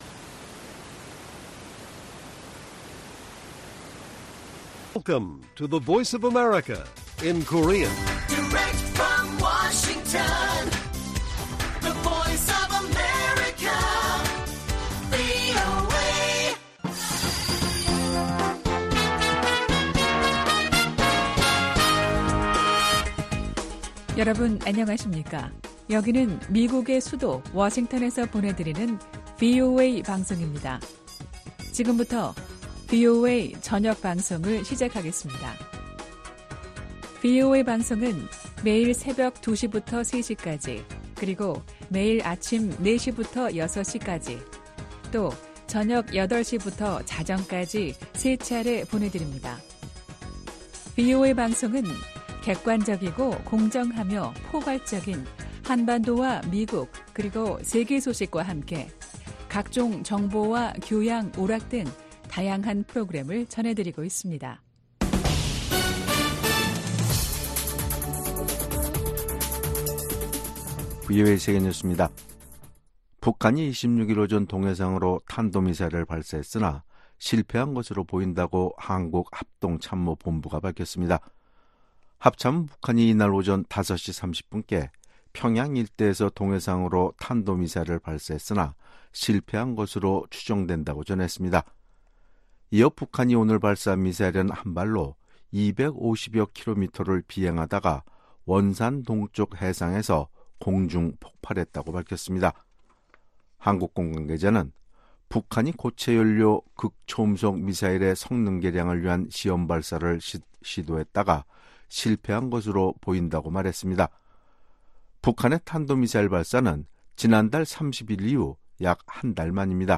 VOA 한국어 간판 뉴스 프로그램 '뉴스 투데이', 2024년 6월 26일 1부 방송입니다. 북한이 동해상으로 극초음속 미사일로 추정되는 발사체를 쏘고 이틀째 한국을 향해 오물 풍선을 살포했습니다. 미국 정부는 북한의 탄도미사일 발사가 다수의 유엔 안보리 결의 위반이라며 대화에 복귀할 것을 북한에 촉구했습니다. 미국 국방부는 북한이 우크라이나에 병력을 파견할 가능성에 대해 경계를 늦추지 않고 있다는 입장을 밝혔습니다.